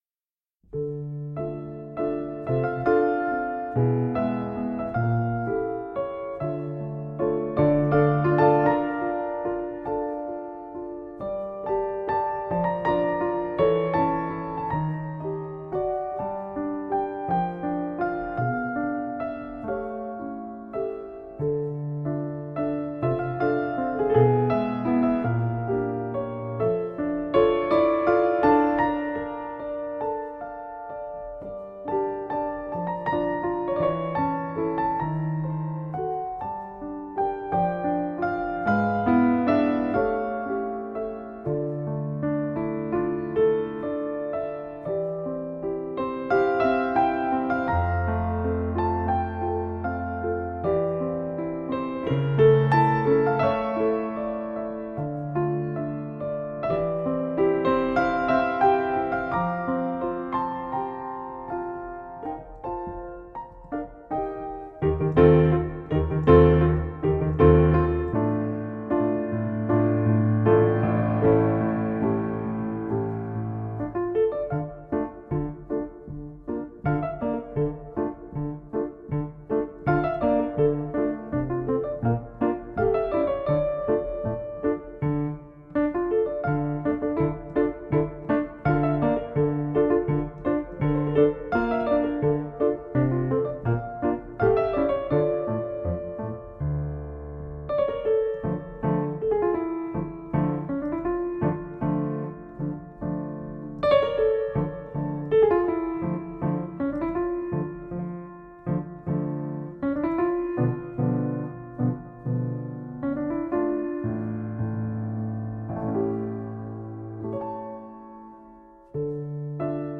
Pop, Classical